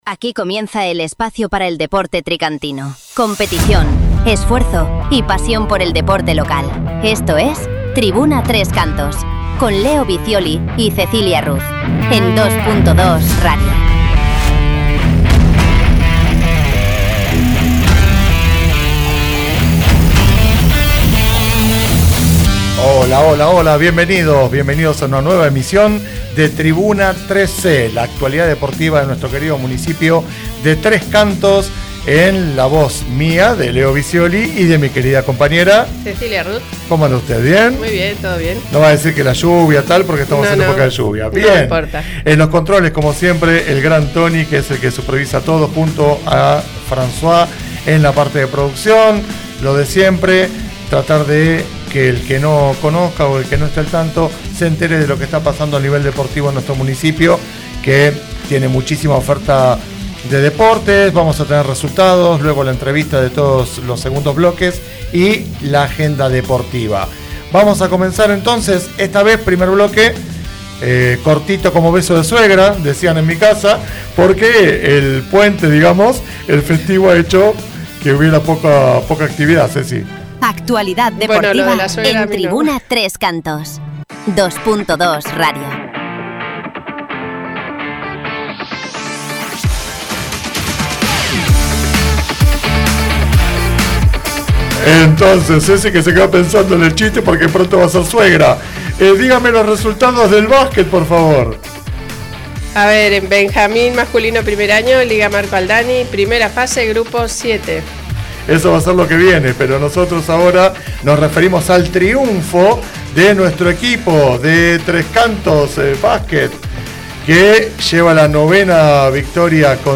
Comentamos los resultados de Golf, Balonmano, Waterpolo,fútbol y baloncesto. En el bloque 2 entrevistamos a Marcha Nórdica 3c para conocer su evolución, sus actividades y los resultados de la reciente carrera.